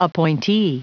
Prononciation du mot appointee en anglais (fichier audio)
Prononciation du mot : appointee